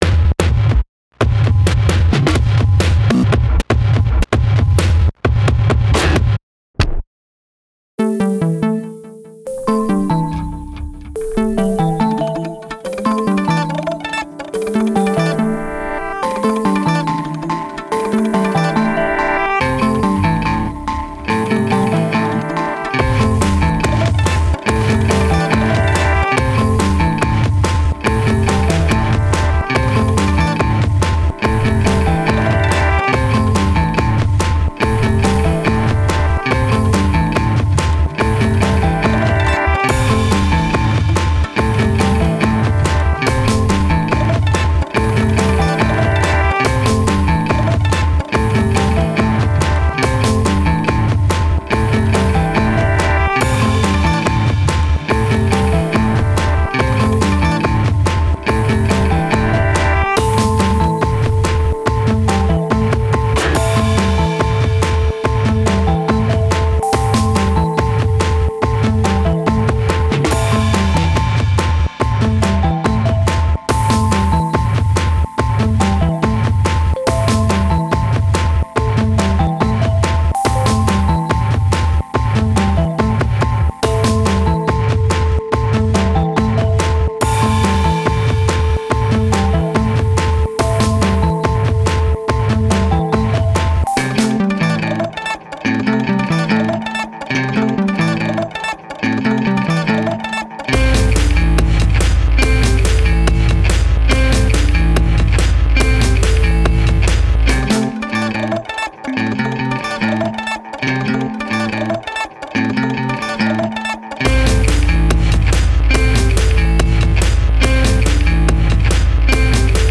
psychedelic drone rock
a couple of pop-ambient tracks